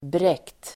Uttal: [brek:t]